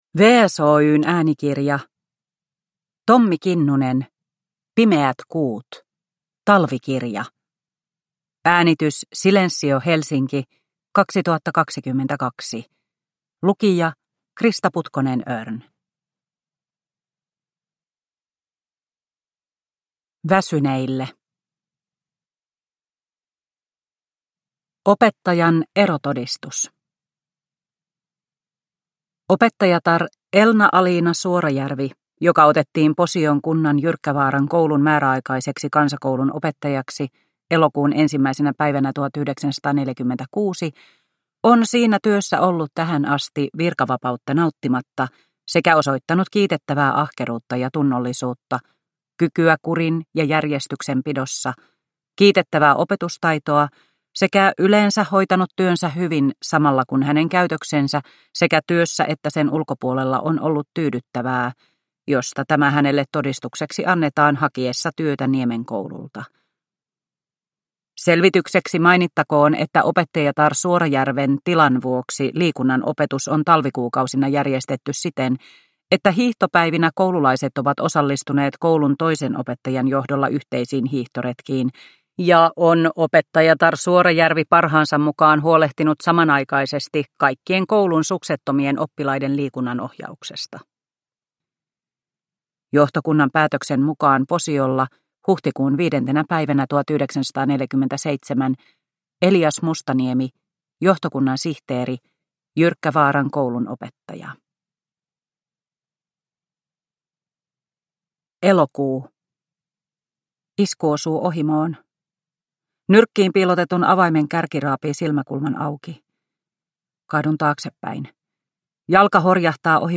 Pimeät kuut – Ljudbok – Laddas ner